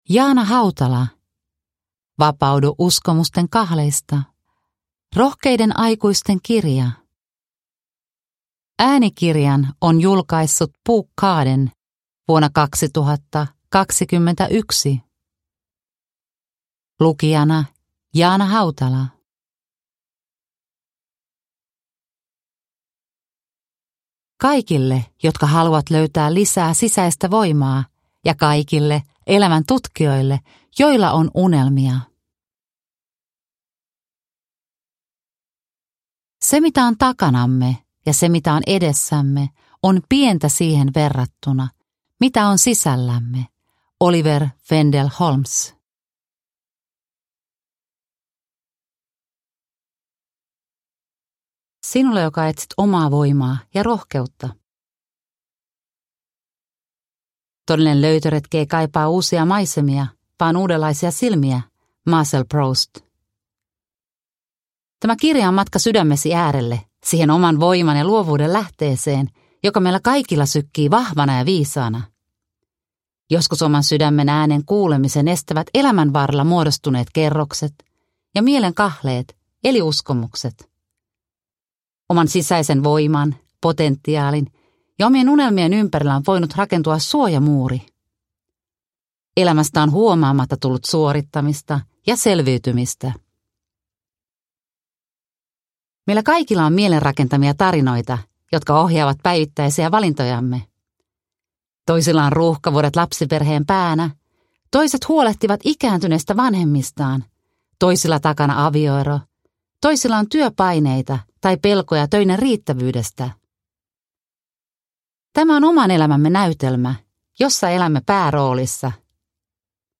Vapaudu uskomusten kahleista – Ljudbok – Laddas ner